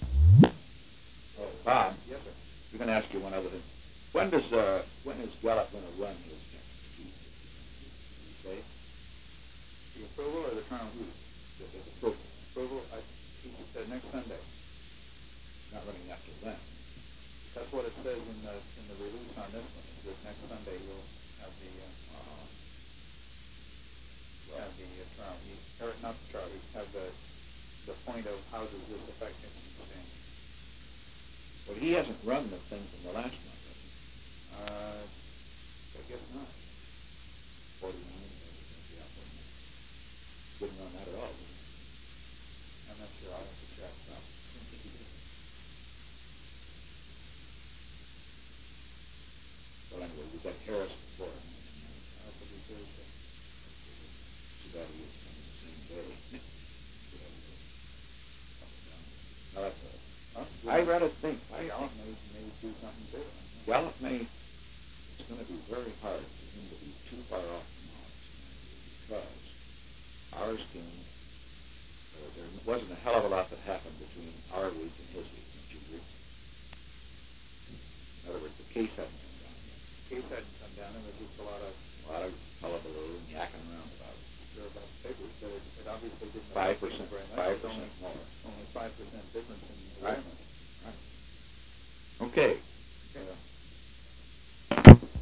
Location: White House Telephone
The President talked with H.R. (“Bob”) Haldeman.